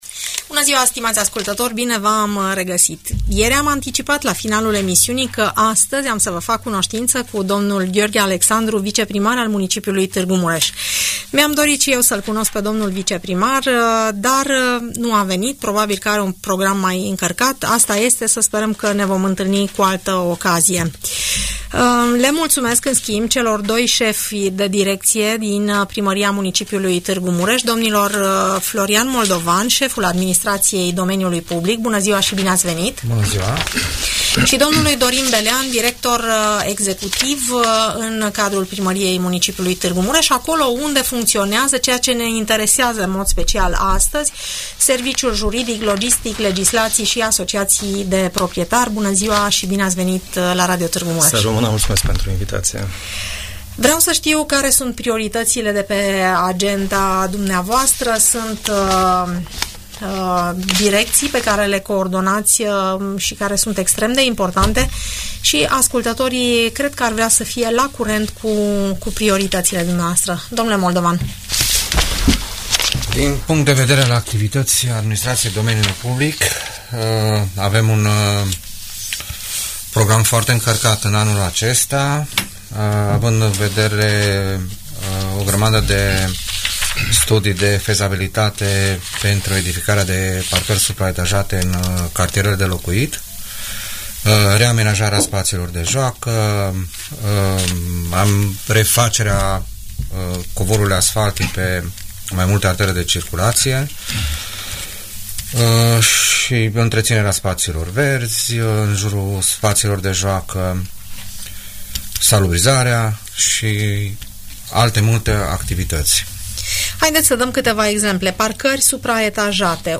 Audiență în direct, la Radio Tg.